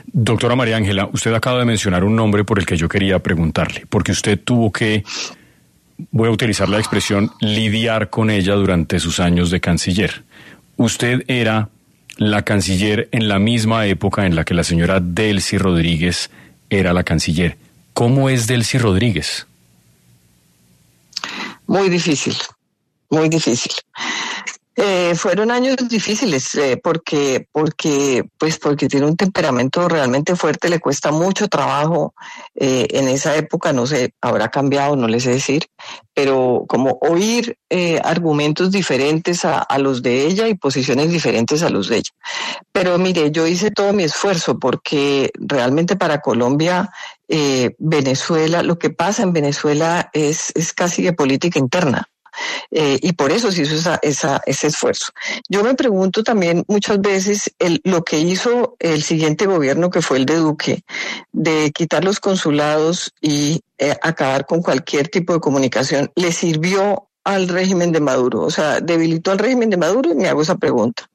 La excanciller colombiana María Ángela Holguín habló en los micrófonos de 6AM W, con Julio Sánchez Cristo, sobre la situación en Venezuela que desencadenó la captura de Nicolás Maduro iniciando el 2026, y habló sobre un nombre clave en el tema: la presidenta encargada de ese país, Delcy Rodríguez.